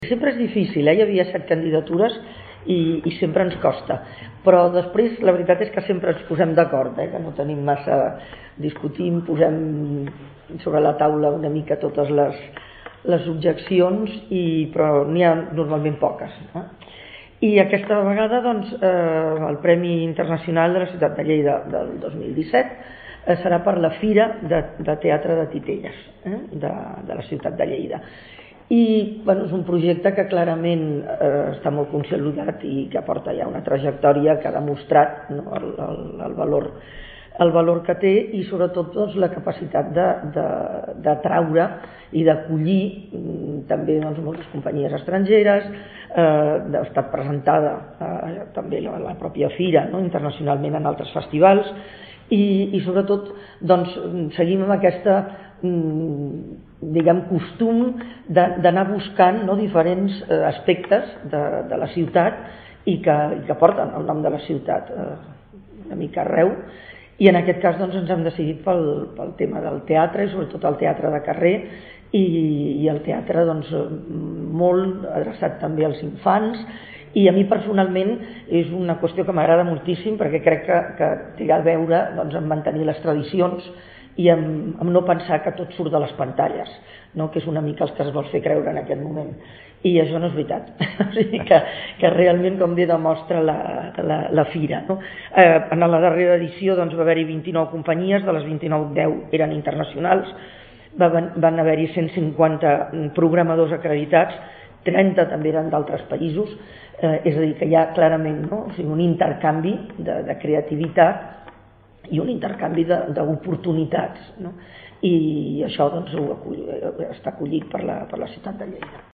tall-de-veu-de-la-presidenta-del-jurat-rosa-maria-calaf-sobre-el-veredicte-del-premi-internacional-ciutat-de-lleida-2017